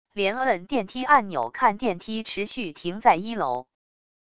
连摁电梯按钮看电梯持续停在一楼wav音频